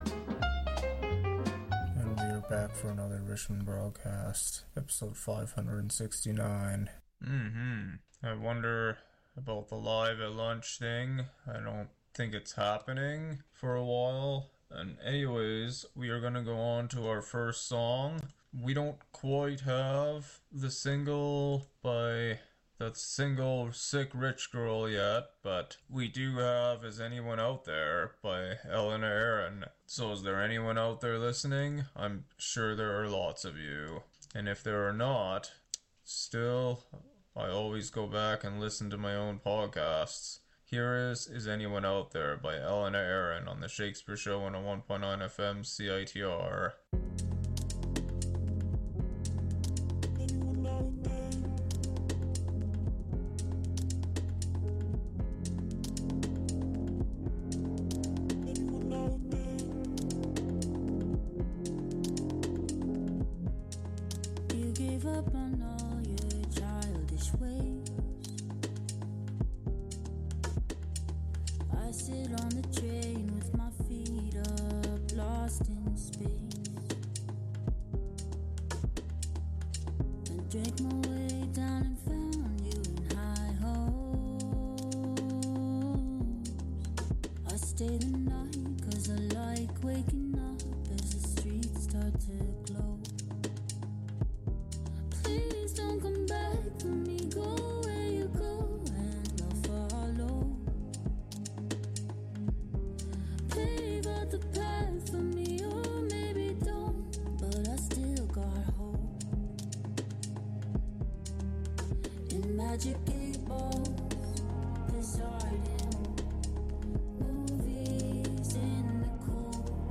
12:58pm an eclectic mix of music Download this podcast Track Listing